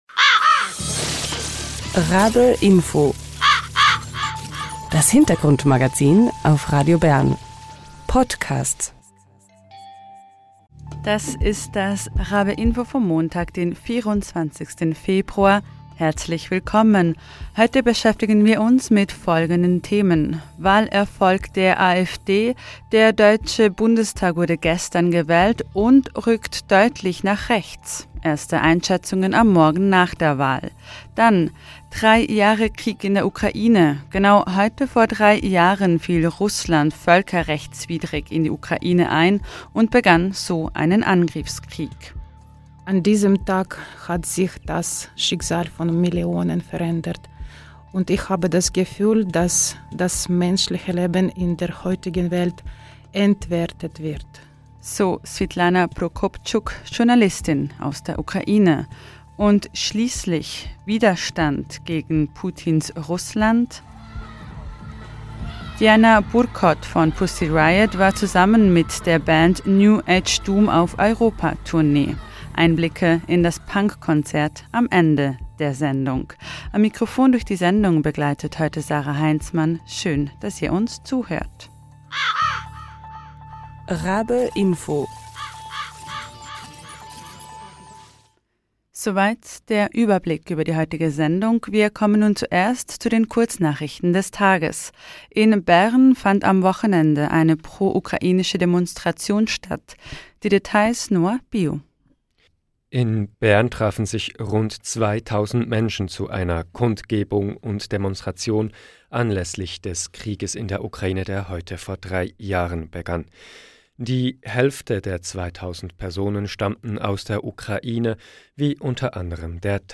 Einblicke in das Konzert in Biel am Ende der Sendung.